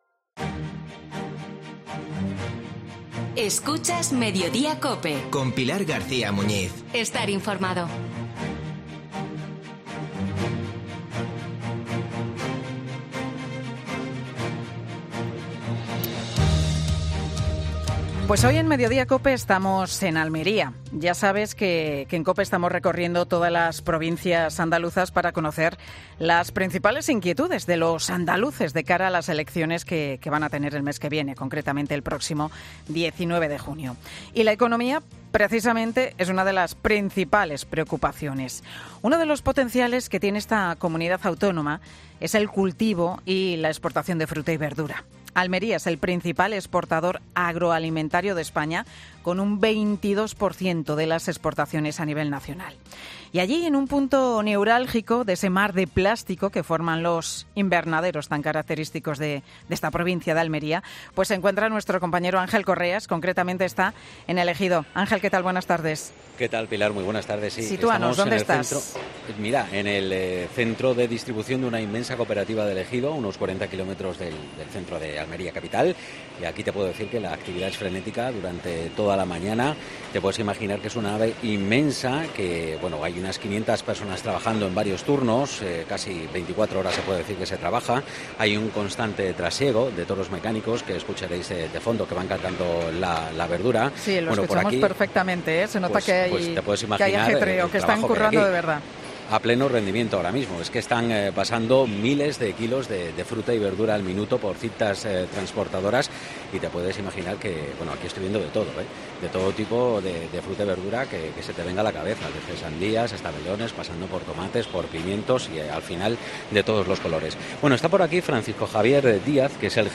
'Mediodía COPE' se ha desplazado hasta Almería para conocer al sector hortofrutícola